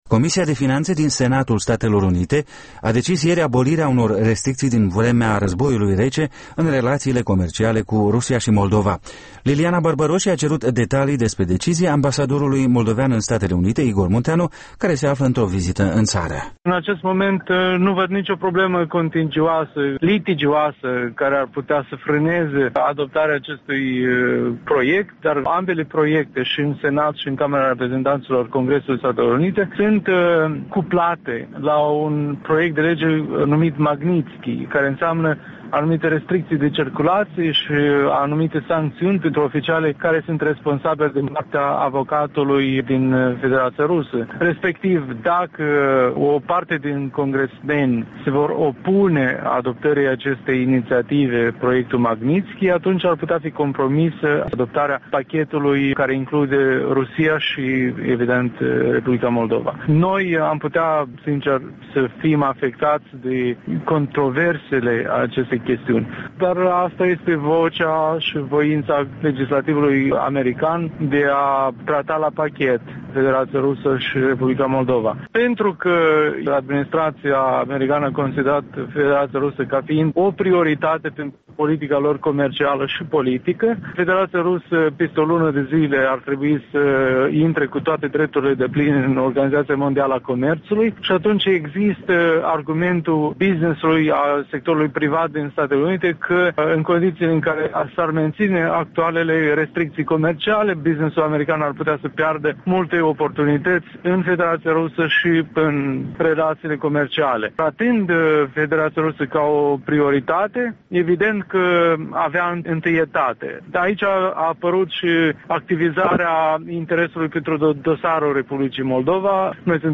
Igor Munteanu, ambasadorul Moldovei la Washington răspunde întrebărilor Europei Libere